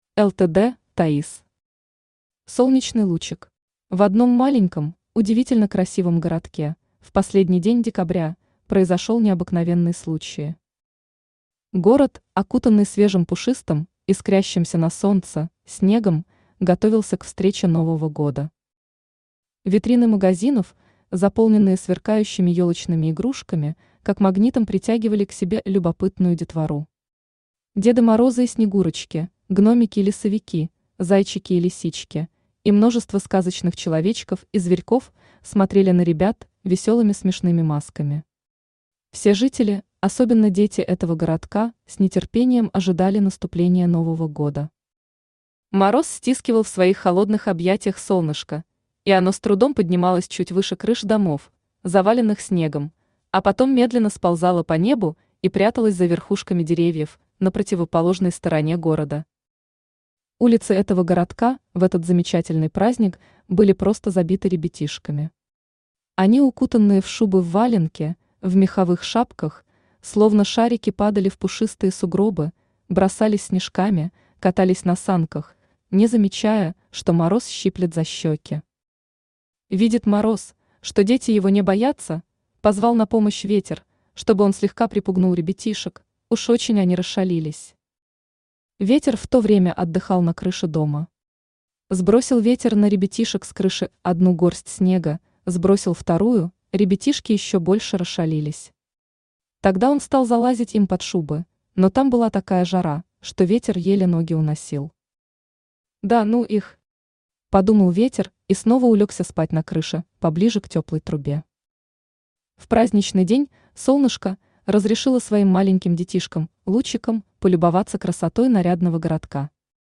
Аудиокнига Солнечный лучик | Библиотека аудиокниг
Aудиокнига Солнечный лучик Автор ЛТД Таисс Читает аудиокнигу Авточтец ЛитРес.